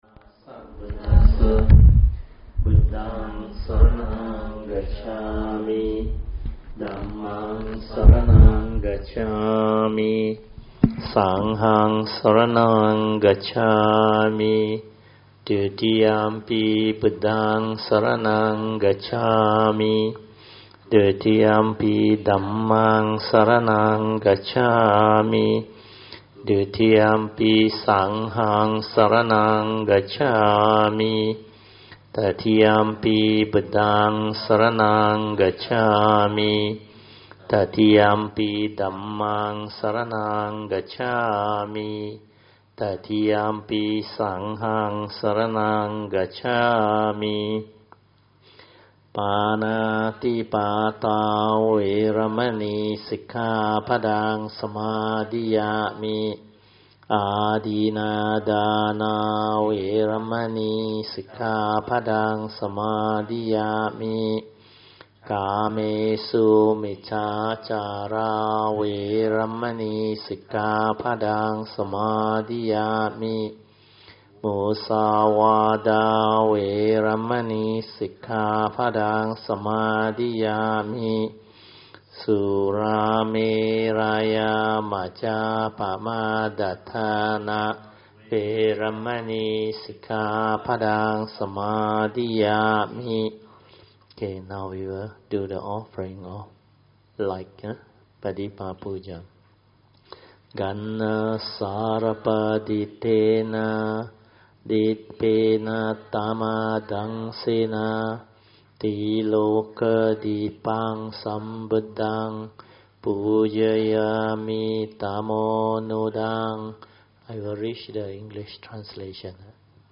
Tuesday Class